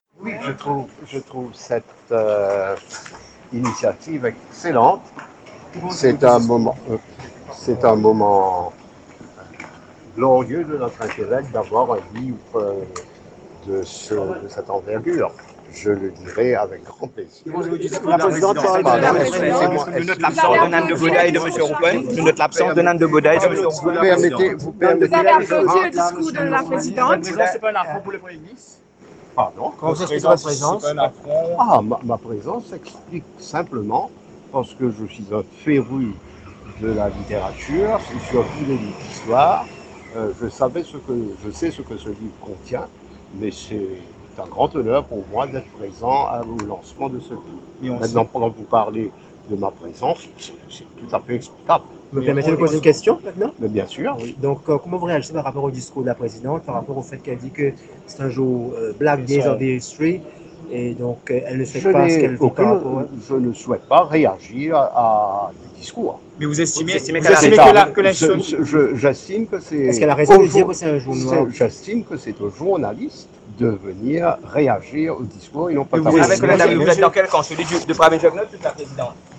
Il était présent au lancement du livre d’Ameenah Gurib-Fakim, Du Réduit à la State House, à Réduit, ce mercredi 7 mars. À sa sortie, le ministre de l’Énergie, Ivan Collendavelloo a été interrogé sur le discours de la présidente, qui a affirmé ne rien à voir à se reprocher.